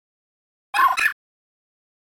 Archivo:Grito de Riolu.ogg